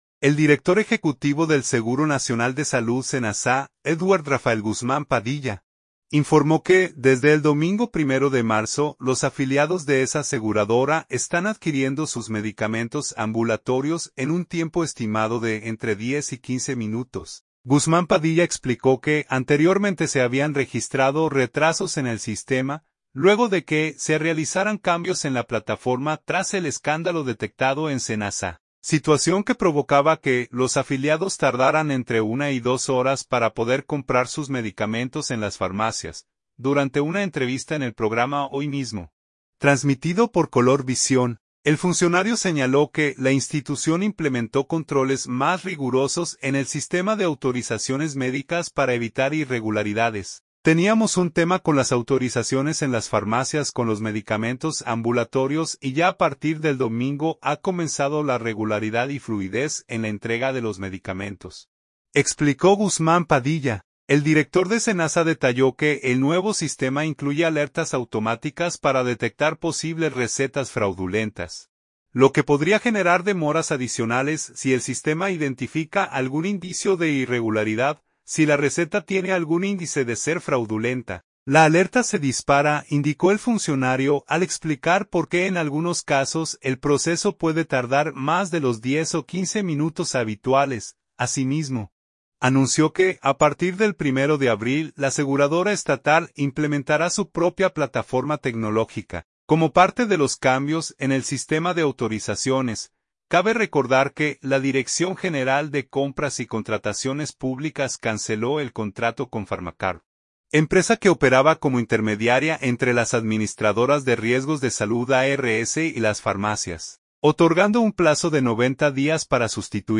Durante una entrevista en el programa “Hoy Mismo”, transmitido por Color Visión, el funcionario señaló que la institución implementó controles más rigurosos en el sistema de autorizaciones médicas para evitar irregularidades.